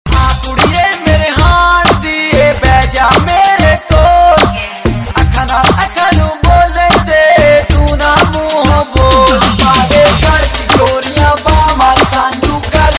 Category: Punjabi Ringtones